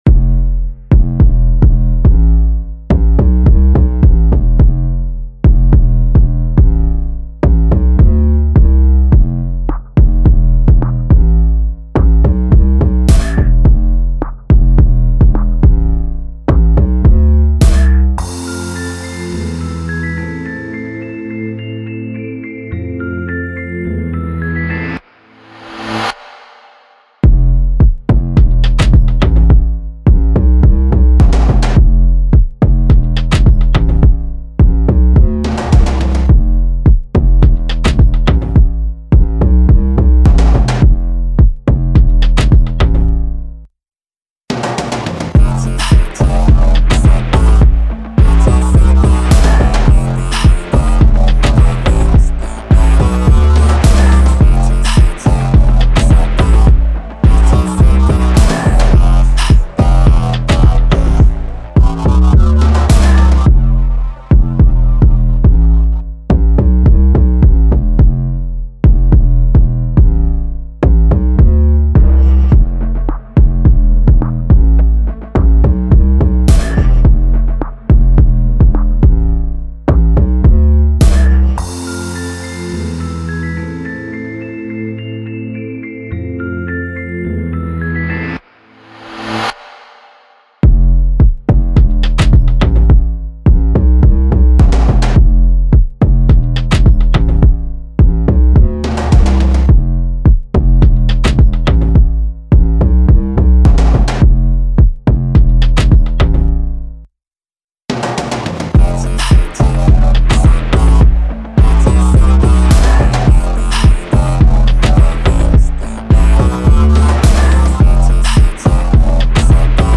This is the official instrumental
Pop Instrumentals